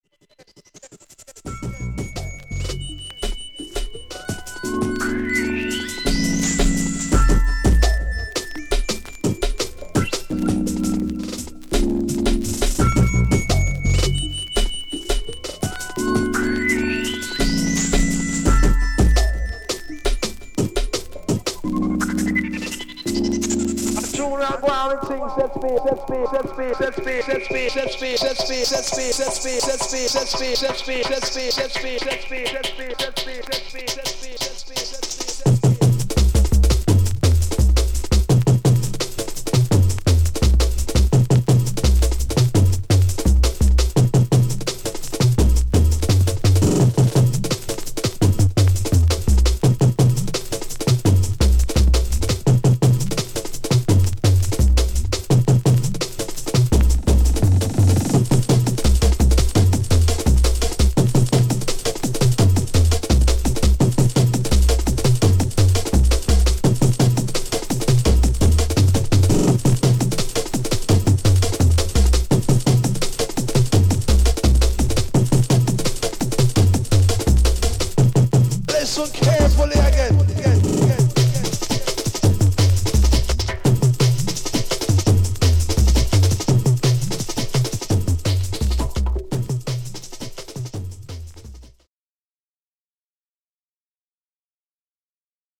＊視聴音源は実物のレコードから録音してます。